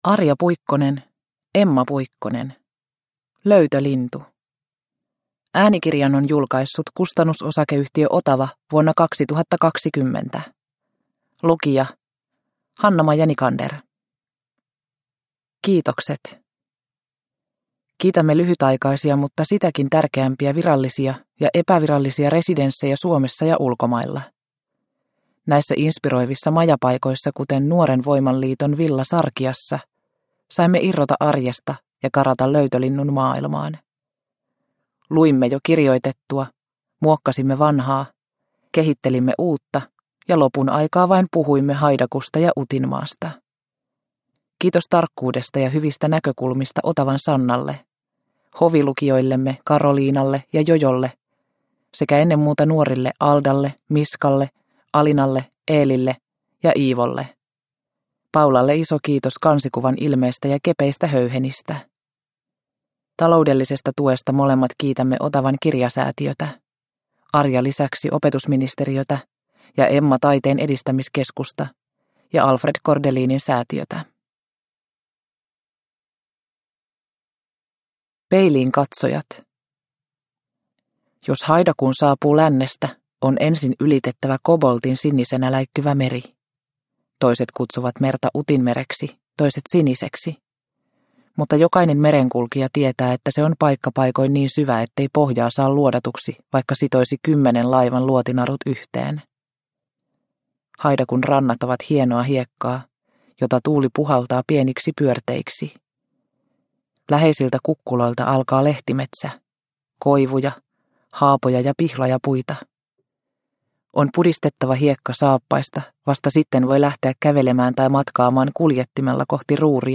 Löytölintu – Ljudbok – Laddas ner